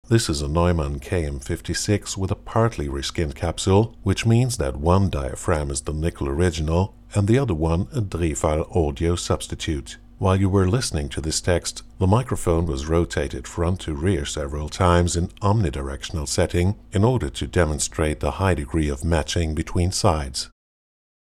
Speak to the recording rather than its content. The result: Fully restored frequency response and reliability, superb front/rear balance for perfect omni and figure-of-eight pattern. Audio demo KK56 reskinned